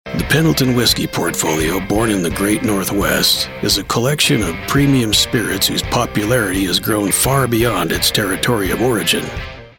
• Clean, broadcast‑ready audio from a professional studio
Just a real voice with real grit.
Commercial Voice Over Demos